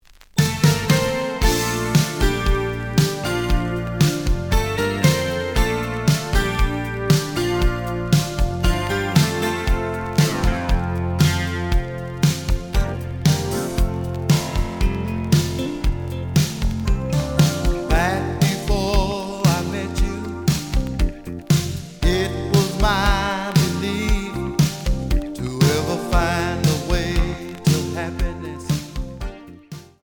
The audio sample is recorded from the actual item.
●Genre: Soul, 80's / 90's Soul
Looks good, but slight noise on A side.)